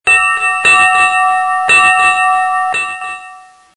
Категория: Живые звуки, имитация